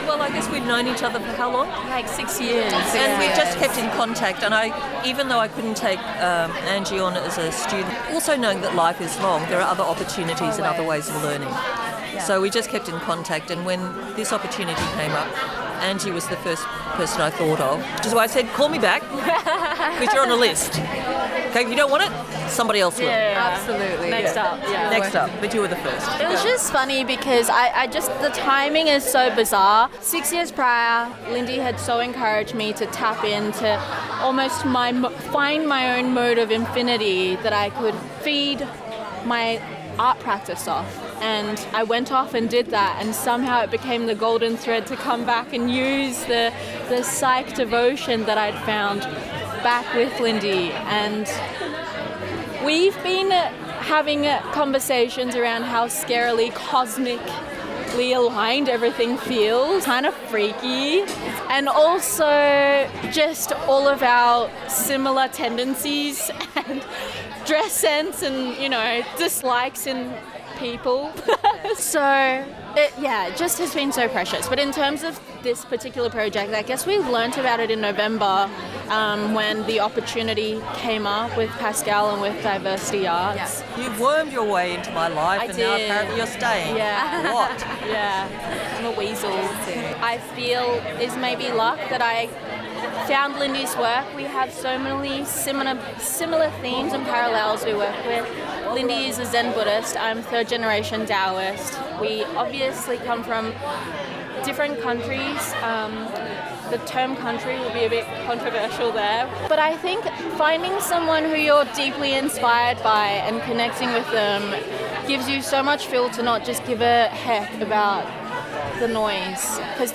Listen to the voices of intergenerational artists from underrepresented backgrounds in our StoryCasters podcast vox pop interviews, recorded live at the InterGenerate Presentation hosted by Diversity Arts Australia and the Sydney Opera House. Through mentorship, exchange, and deep storytelling, established artists share their journeys, challenges, and strategies to empower the next generation in navigating the arts industry.